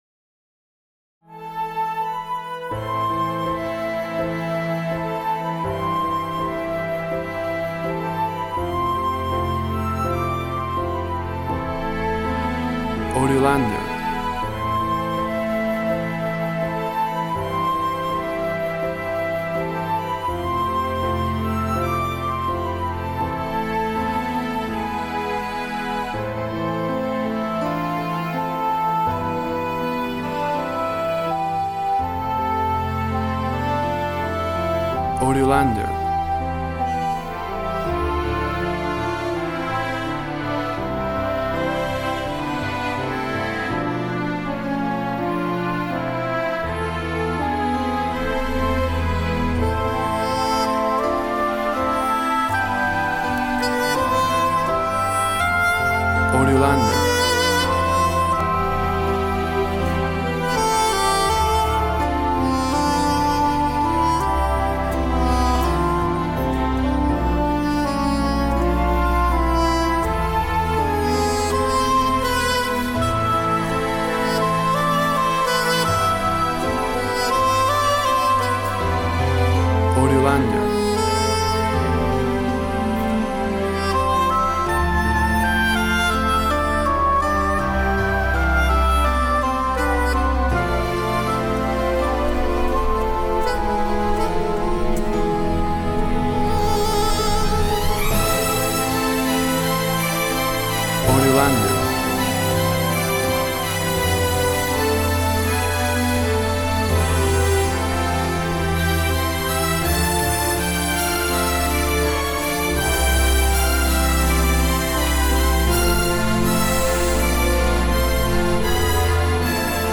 Orchestra and soprano sax.
Tempo (BPM) 80